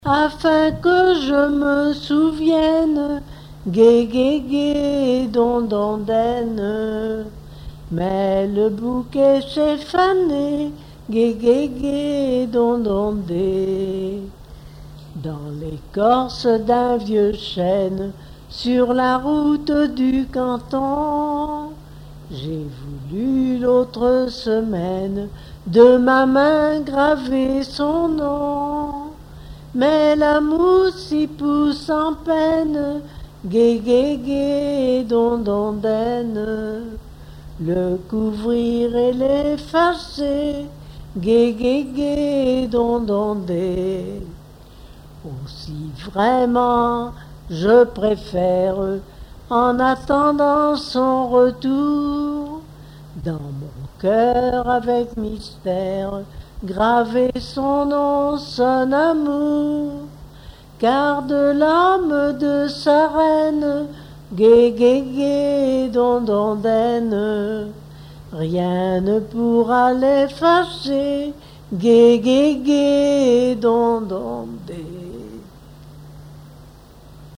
Genre strophique
Enquête Arexcpo en Vendée-C.C. Rocheservière
Pièce musicale inédite